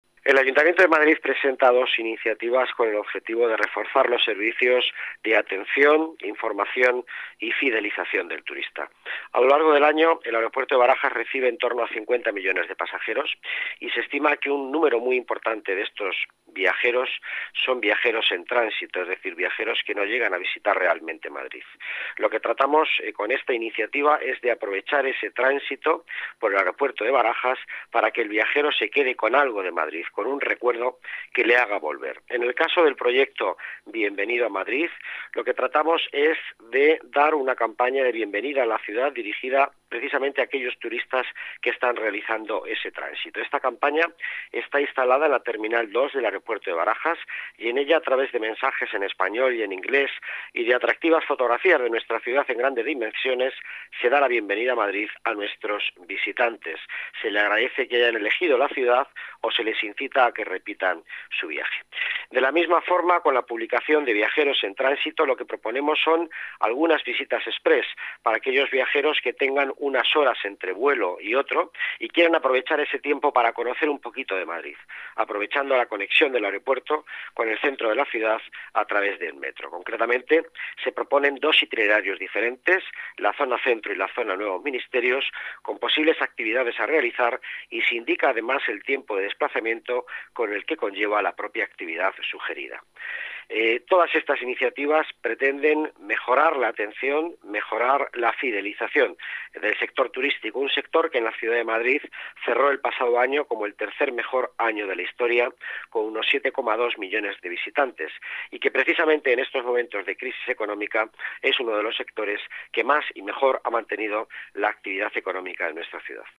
Nueva ventana:Declaraciones del delegado de Economía y Empleo, Miguel Ángel Villanueva